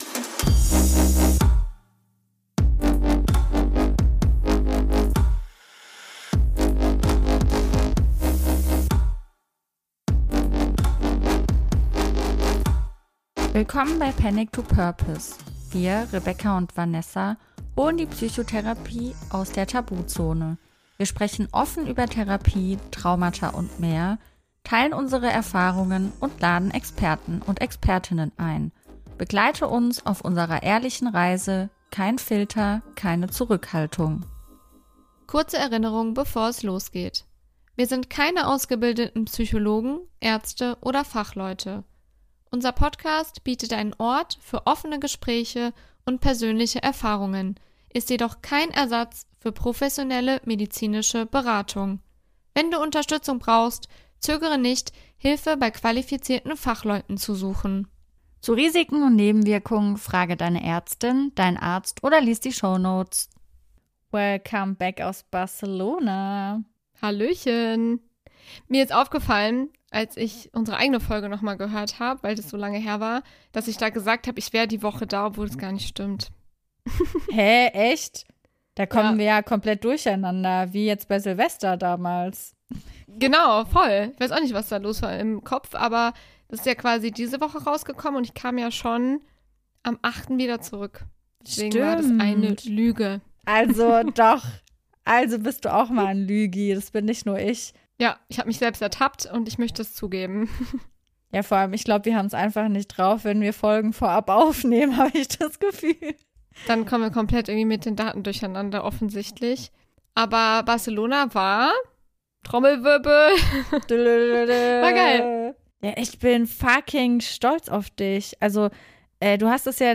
Wir sprechen wie immer offen, locker und mit einer Menge Humor über die Ursachen dieser Ängste und warum sie häufig unterschätzt werden.